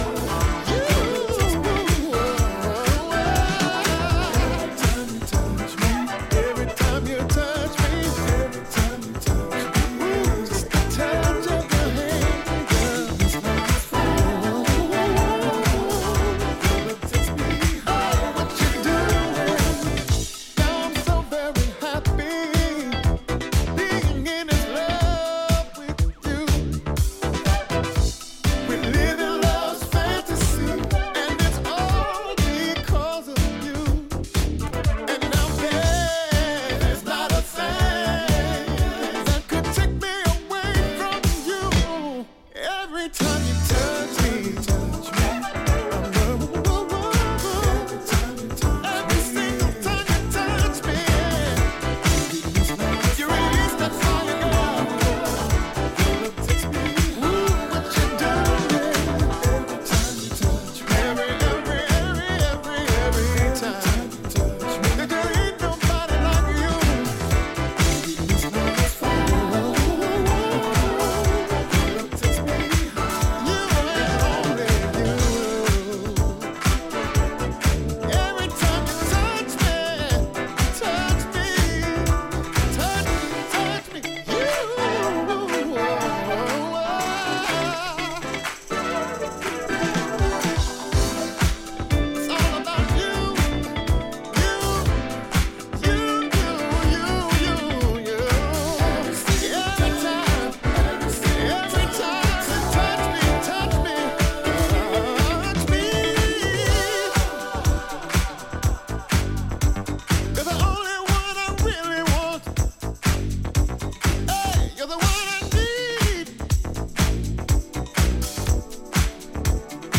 with irresistible Moog basslines